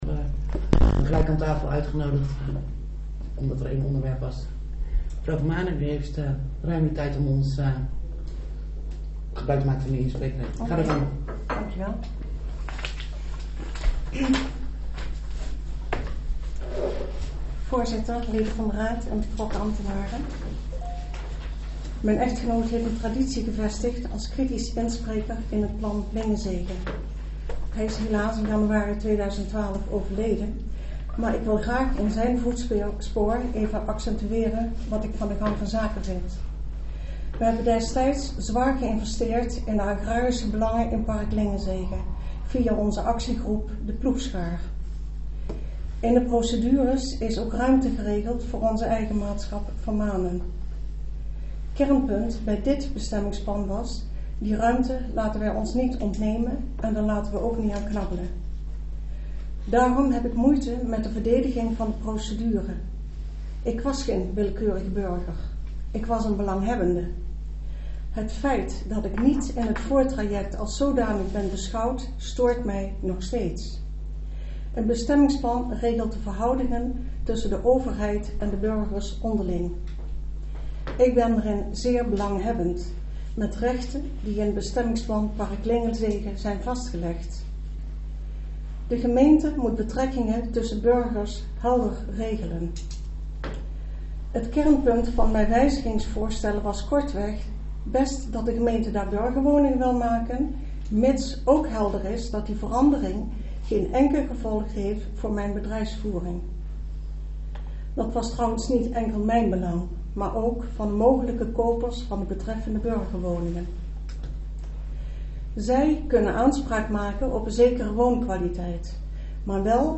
Locatie gemeentehuis Elst Voorzitter mevr.